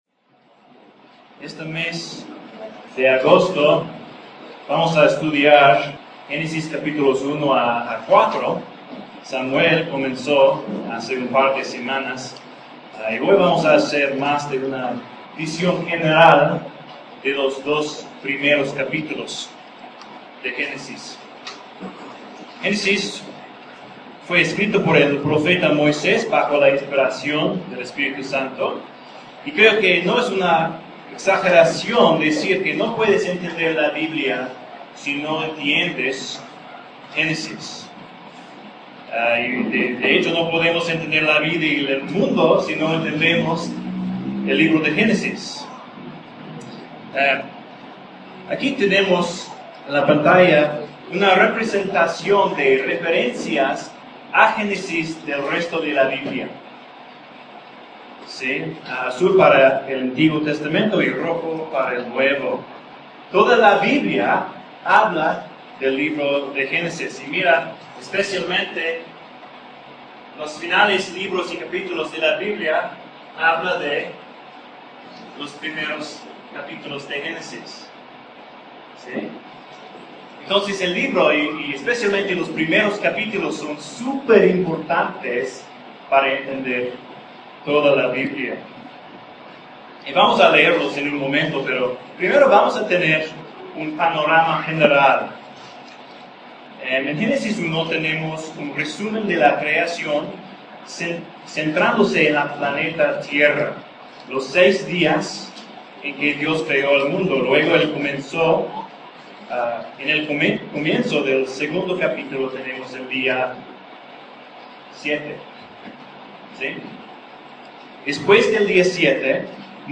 Un sermón de Génesis 1 y 2.
Génesis 1 y 2 (sermón)